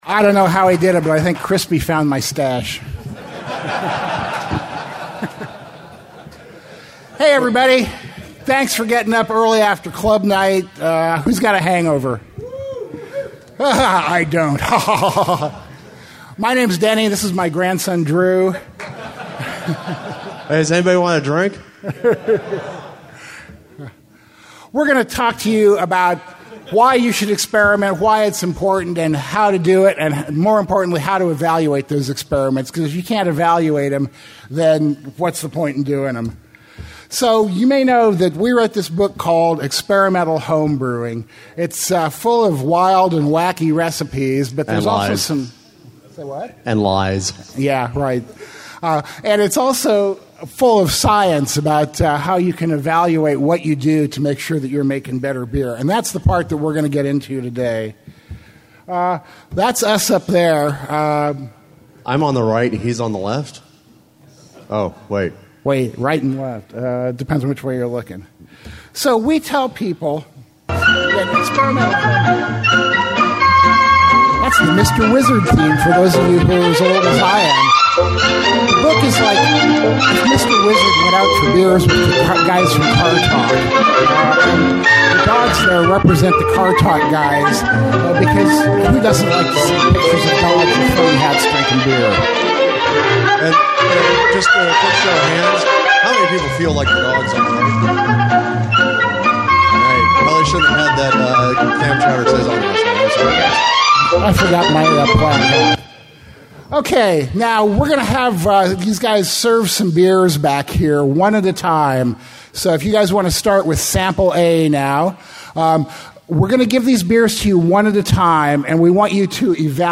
2015 AHA Conference Talk Slides/Audio Now Available!
All of the seminars and speeches are recorded by the AHA and offered as a member perk.